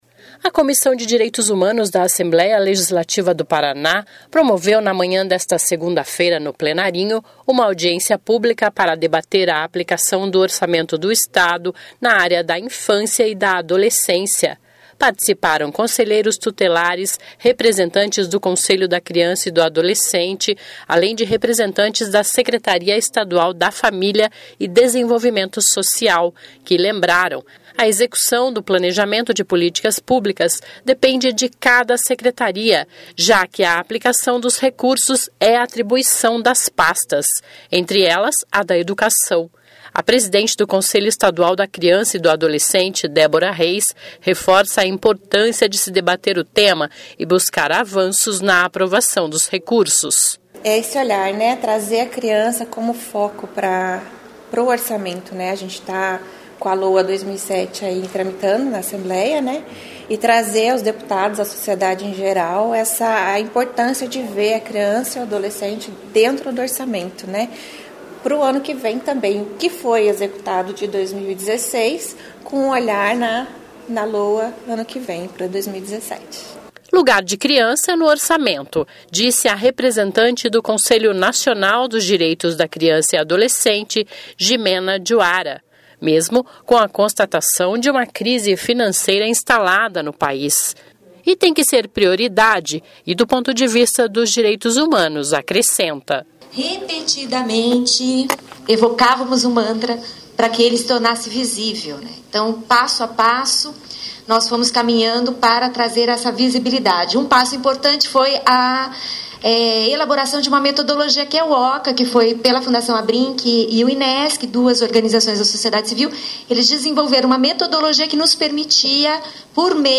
(Descrição do áudio))A Comissão de Direitos Humanos da Assembleia Legislativa do Paraná promoveu, na manhã desta segunda-feira (5) no Plenarinho, uma audiência pública para debater a aplicação do orçamento do Estado na área da infância e adolescência.